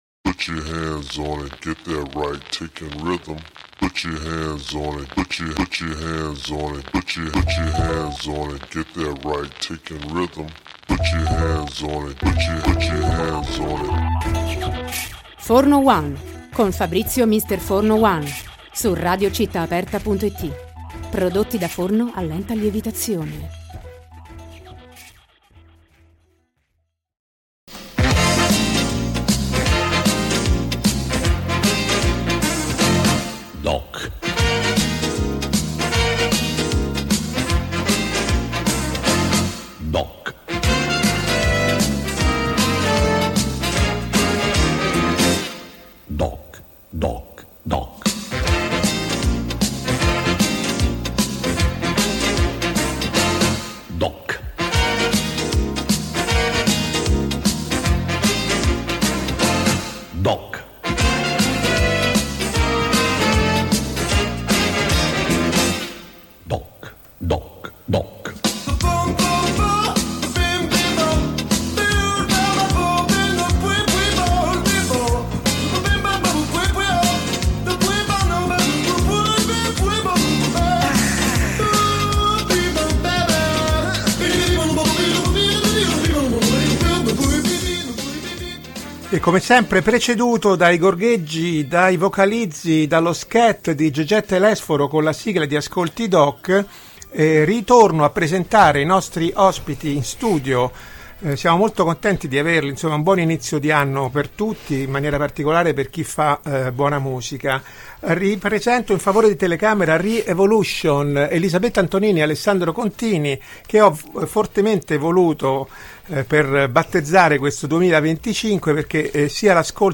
Come da formula collaudata della rubrica, prima dell’intervista vera e propria l’ospite ci hanno portato un brano black del cuore, spiegando ai nostri microfoni le ragioni di questa scelta.